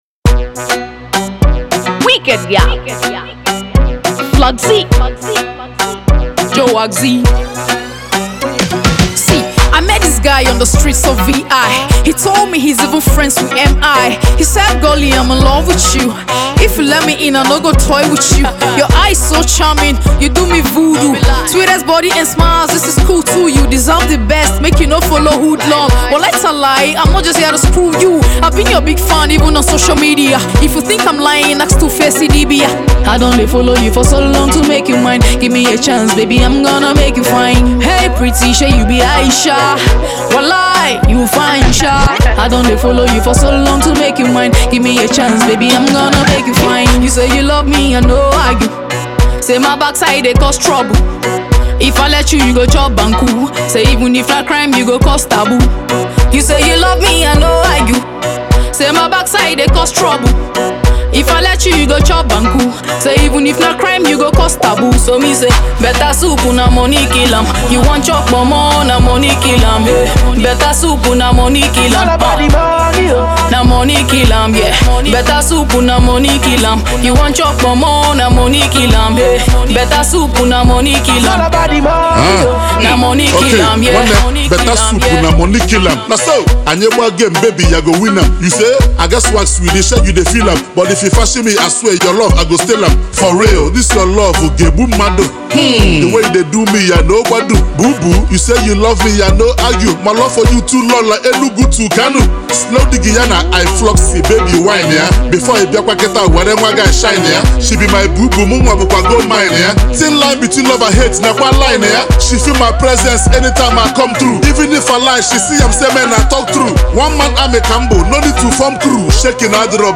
igbo rapper